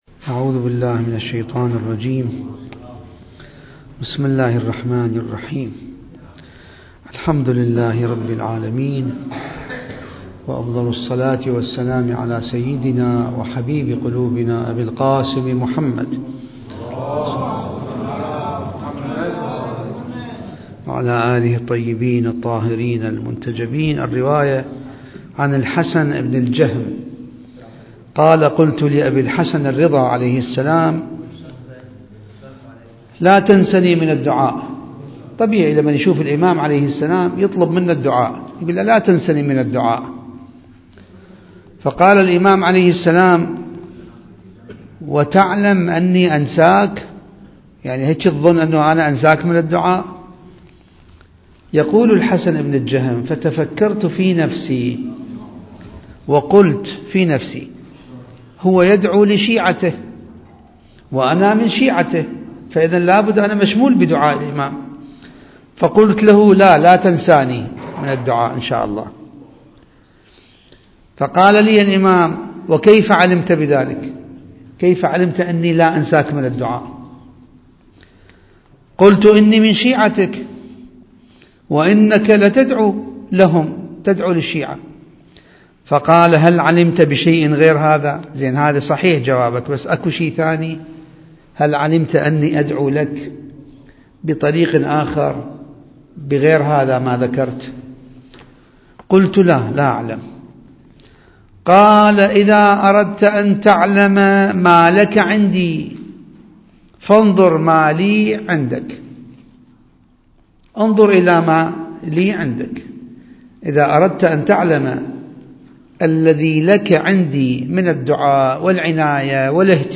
المكان: جامع الصاحب (عجّل الله فرجه) - النجف الأشرف